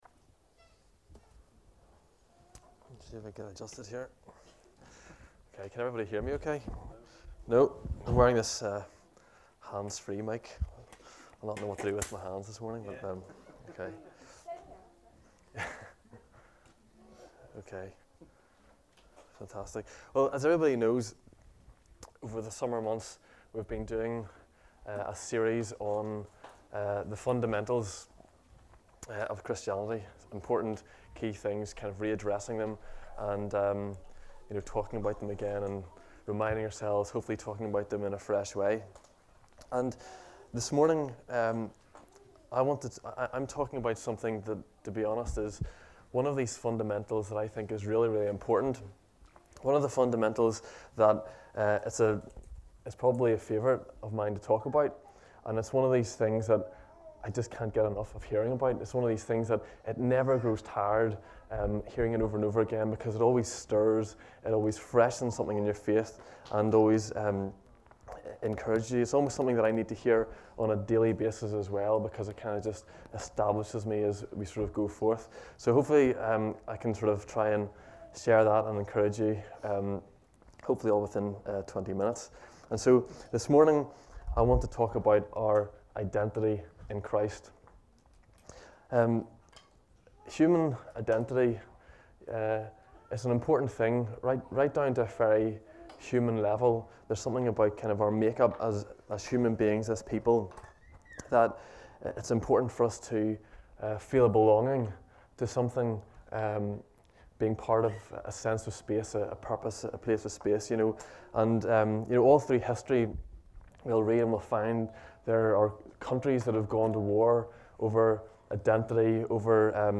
Message: “Our Identity In Christ”